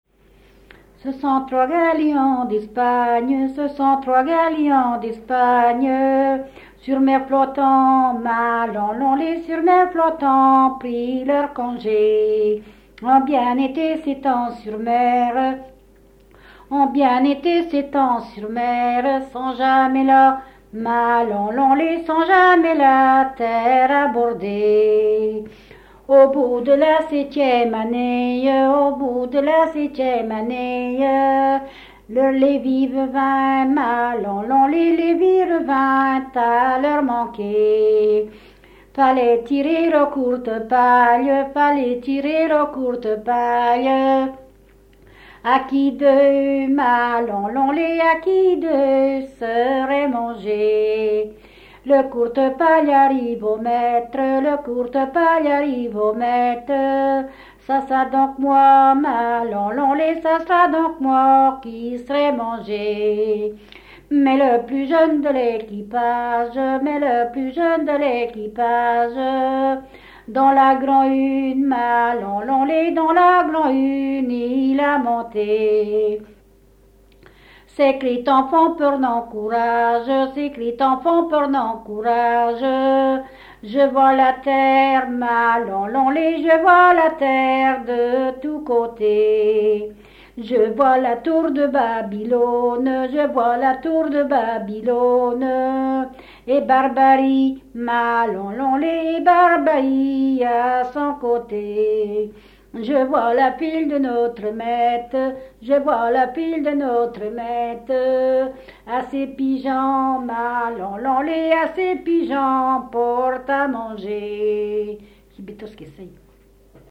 Localisation Barbâtre (Plus d'informations sur Wikipedia)
Fonction d'après l'analyste danse : ronde ;
Genre laisse
Catégorie Pièce musicale inédite